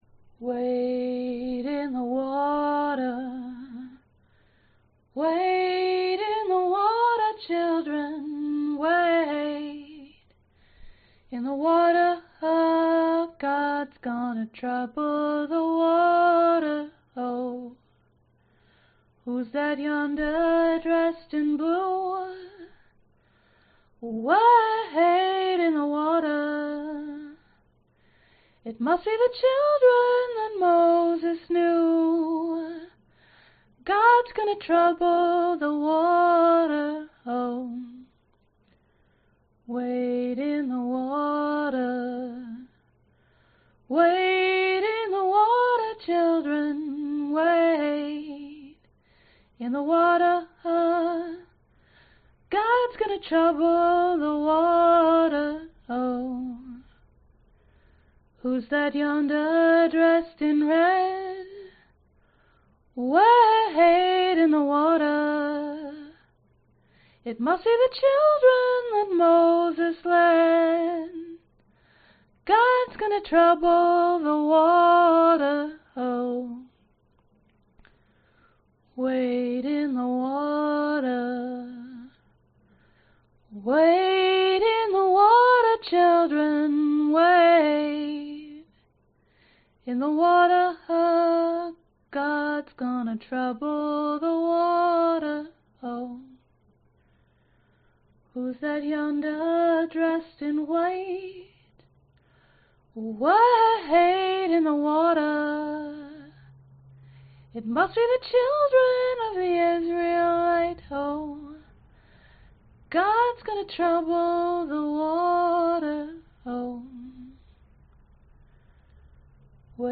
标签： 女声 民谣 鼓声
声道立体声